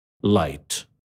How-to-pronounce-LIGHT-in-American-English_cut_1sec.mp3